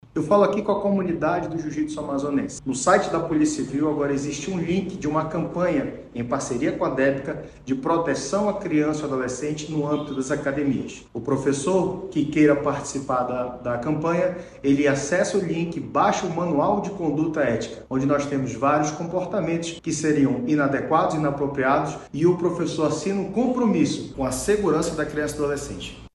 Segundo o delegado-geral adjunto da Polícia Civil do Amazonas, Guilherme Torres, a iniciativa busca garantir um ambiente seguro, respeitoso e livre de violência sexual, com foco especial na proteção de crianças e adolescentes.